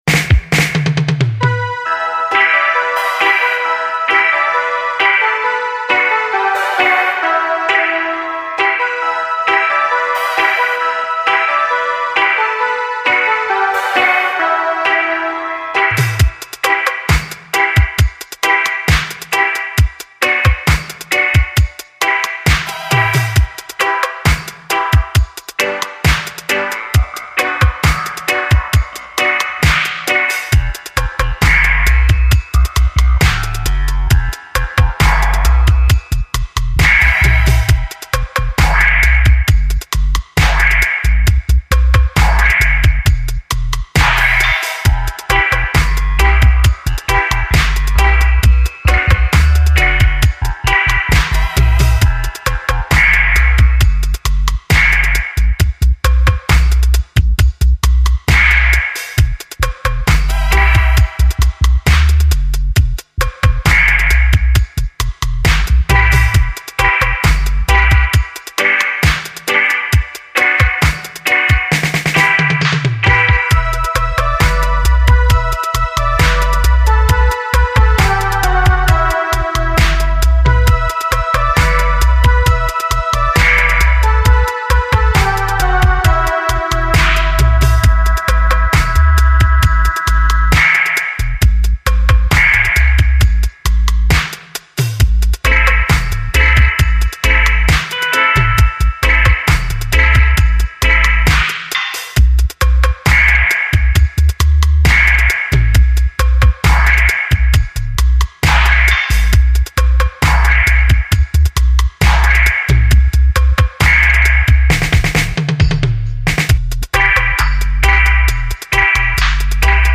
in a righteous and sober mood.